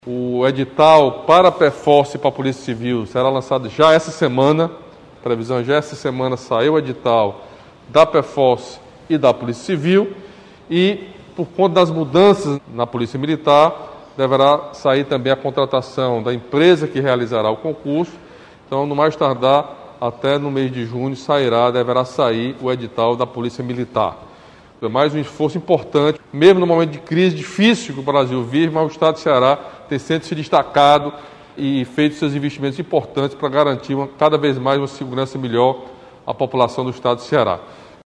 O governador Camilo Santana falou sobre o lançamento dos editais dos concursos e destacou que, apesar do cenário de crise vivenciado em todo o país, o Governo do Ceará continua investindo para garantir a formação de profissionais, além da ampliação, descentralização e modernização dos serviços e estrutura da segurança pública do Ceará.